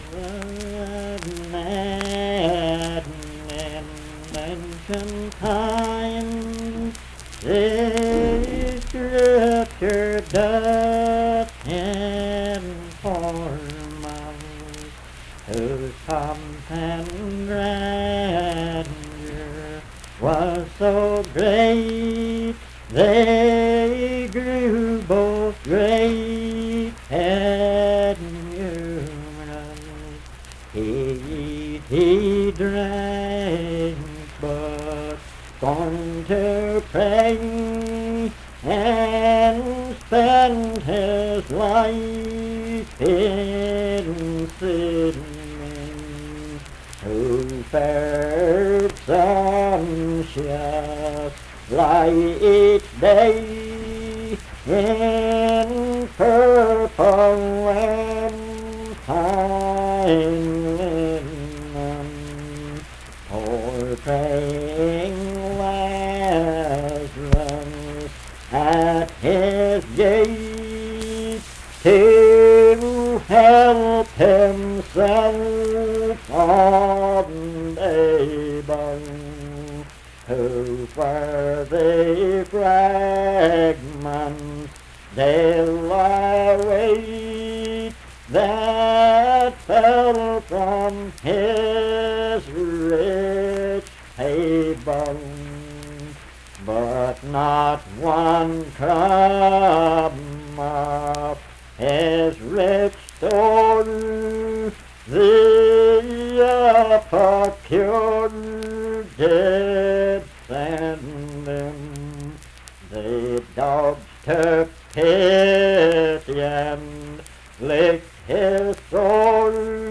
Unaccompanied vocal music
Verse-refrain 11(4).
Performed in Dundon, Clay County, WV.
Hymns and Spiritual Music
Voice (sung)